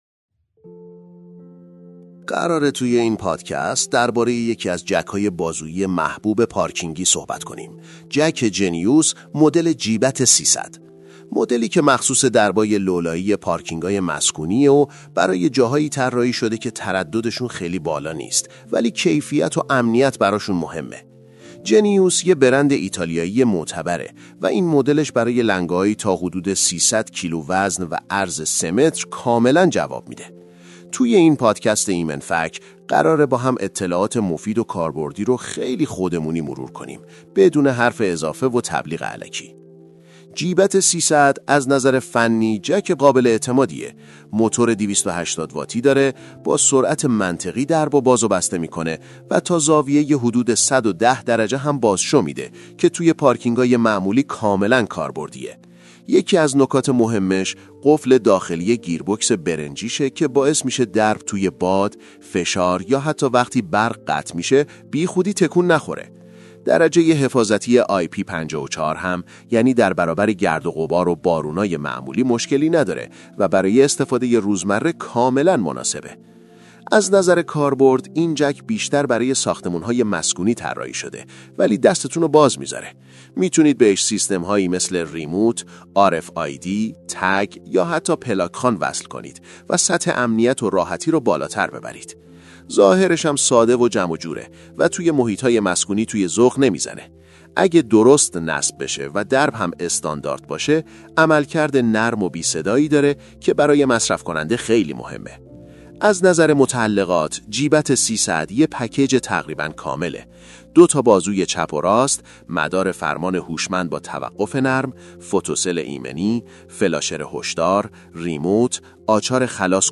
پادکست بررسی و معرفی جک بازویی جنیوس GBAT 300